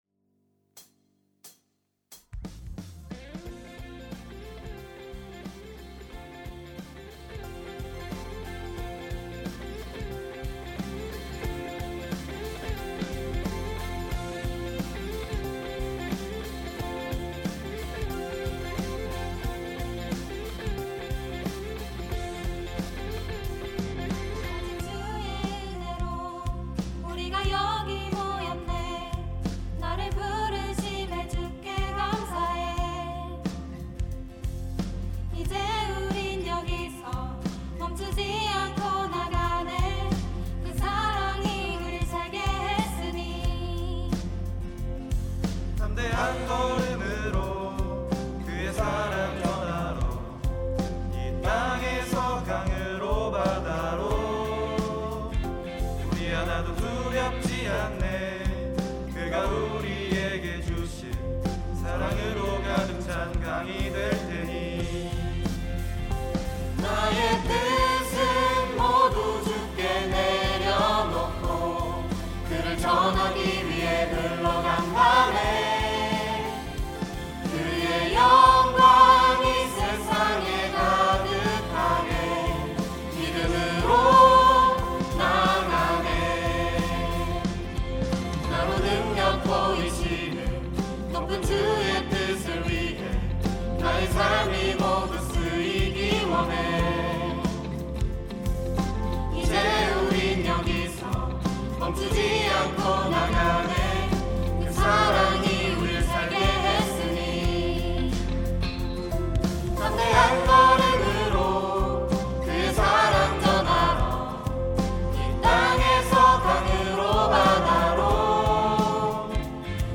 특송과 특주 - River